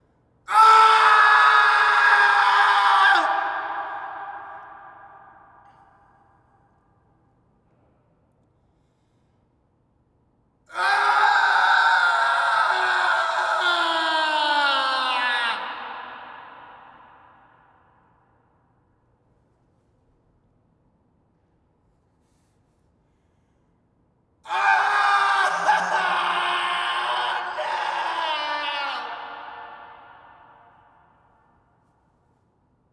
Parking Garage - Walla, Man Scream
Category 🗣 Voices
garage man parking scream screaming walla wild sound effect free sound royalty free Voices